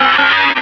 Cri de Ramboum dans Pokémon Diamant et Perle.